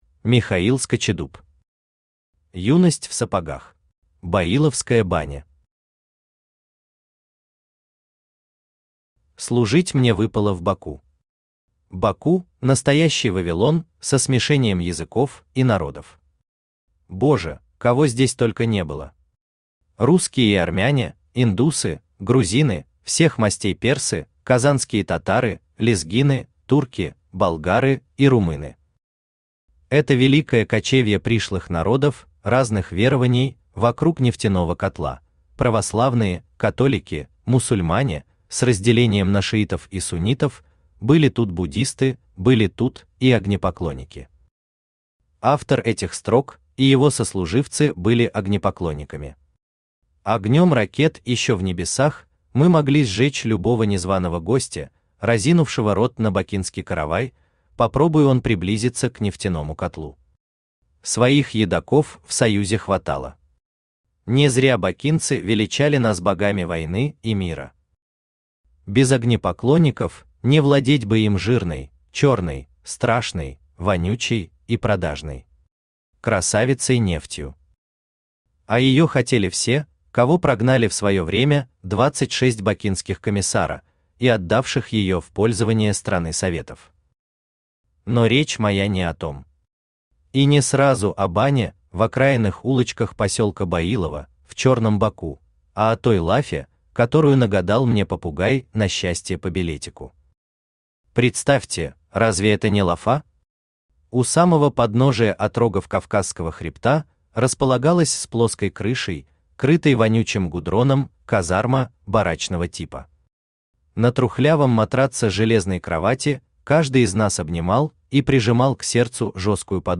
Аудиокнига Юность в сапогах | Библиотека аудиокниг
Aудиокнига Юность в сапогах Автор Михаил Скачидуб Читает аудиокнигу Авточтец ЛитРес.